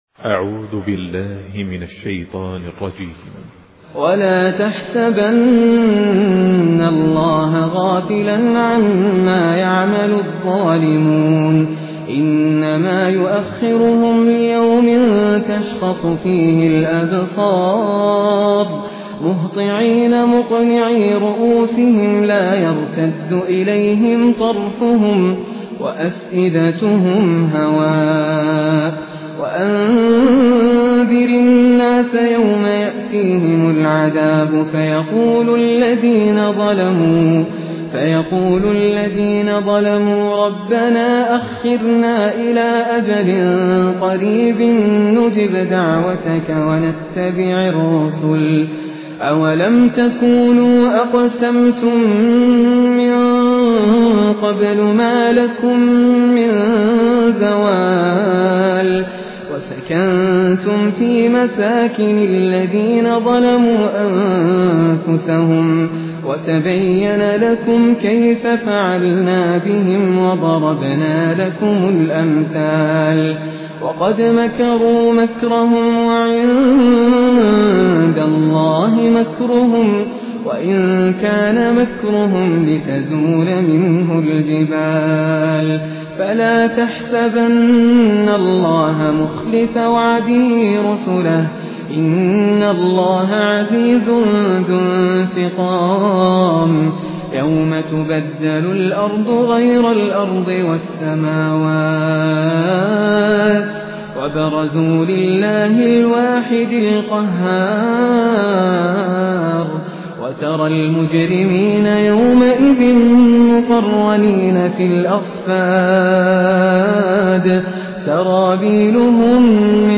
Quran recitations
Humble, distinctive recitations